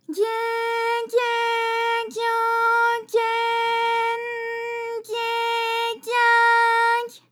ALYS-DB-001-JPN - First Japanese UTAU vocal library of ALYS.
gye_gye_gyo_gye_n_gye_gya_gy.wav